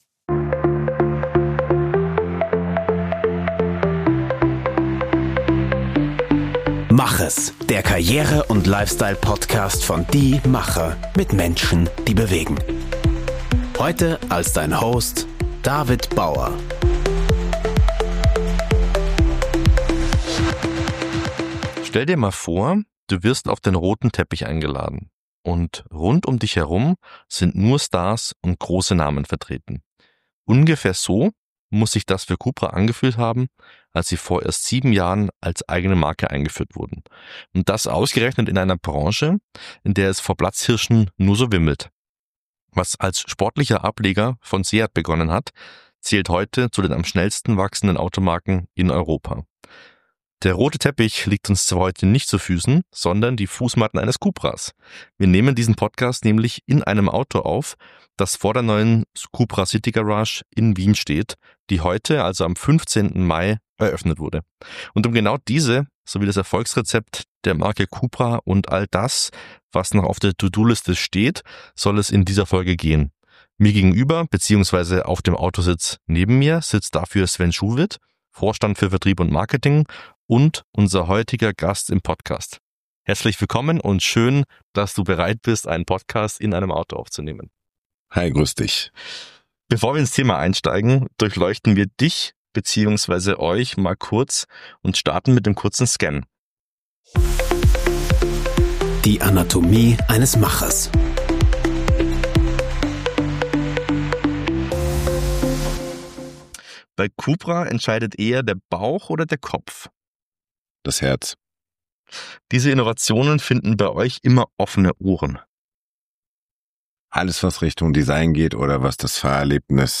In dieser Podcastfolge – die übrigens hinter dem Steuer eines Autos in der Wiener Innenstadt aufgenommen wurde – gibt er praktische Tipps, wie Unternehmen ihre Marke von Grund auf aufbauen und in kurzer Zeit erfolgreich etablieren können.